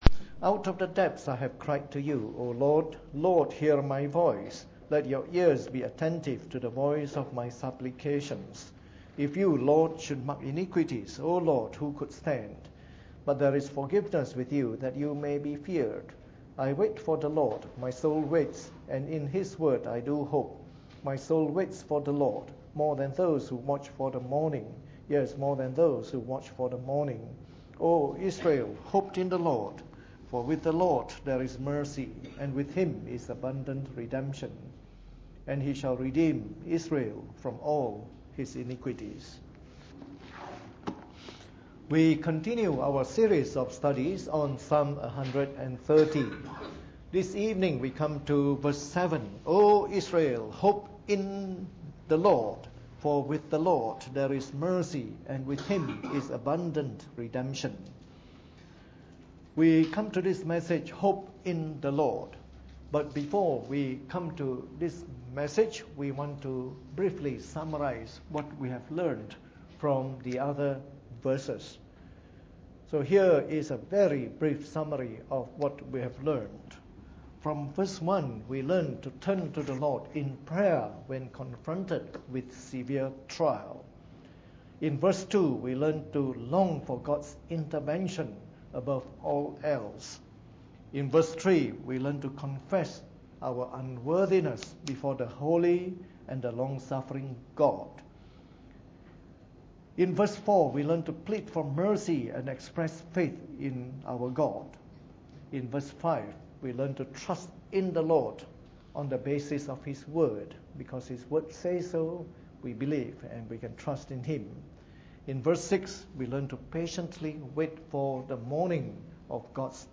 Preached on the 18th of September 2013 during the Bible Study, from our series of talks on Psalm 130.